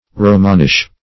Romanish \Ro"man*ish\, a.